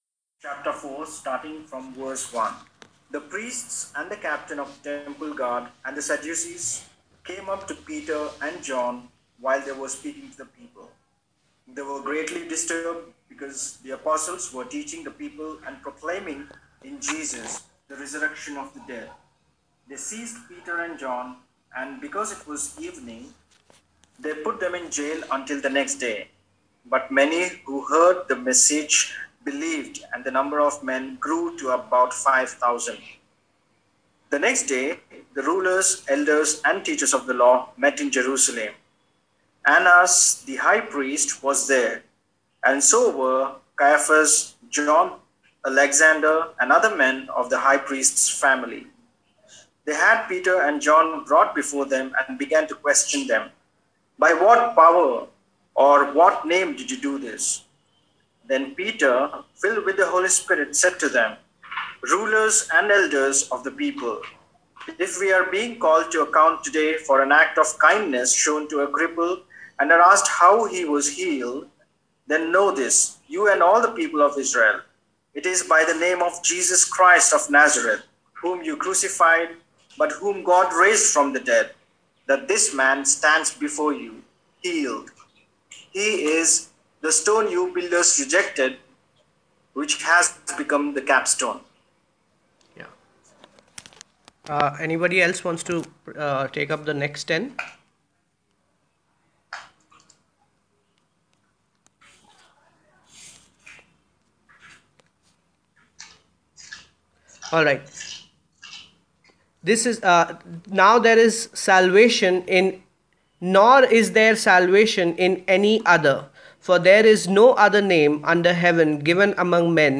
This teaching is a follow up on what we have been doing as a series in the book of Acts.